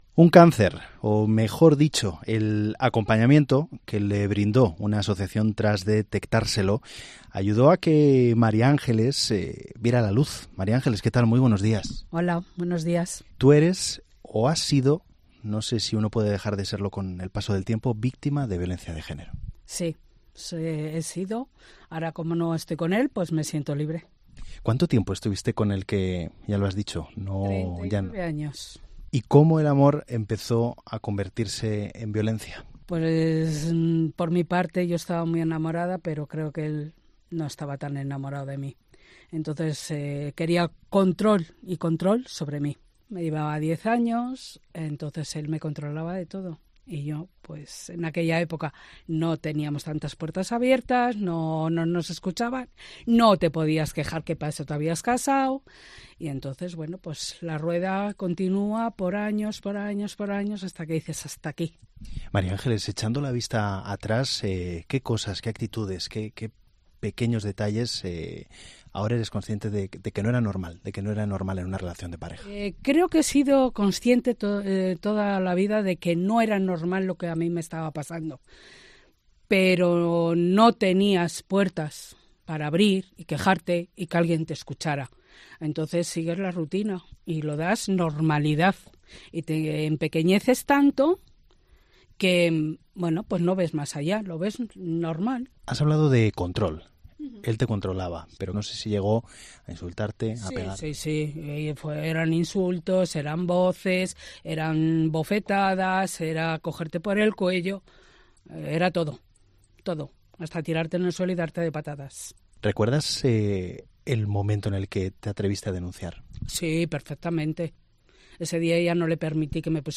Madre e hija, víctimas de la violencia de género, cuentan su testimonio en COPE Valladolid